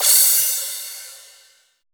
FLANGE CRSH.wav